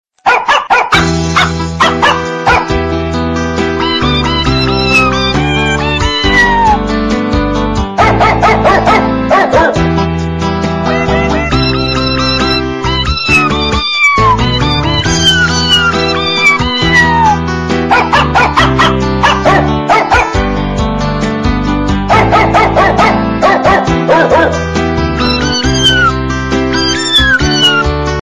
Прикольные звонки